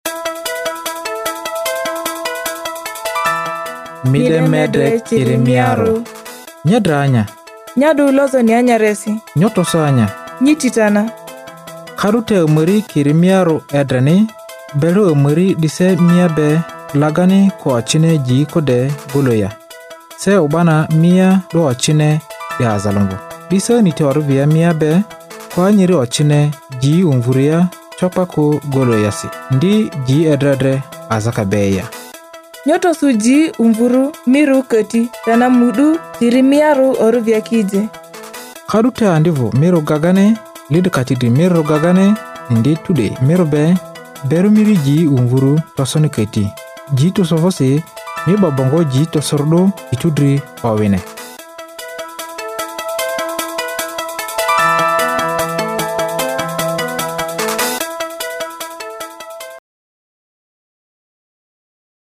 Guinea Worm Public Service Announcements (PSAs)
The Center's Guinea Worm Eradication Program and Office of Public Information developed 12 public service announcements to be read by native African speakers: first to be aired in English, Hausa, and French, followed by Arabic, Fulani, and Bambara.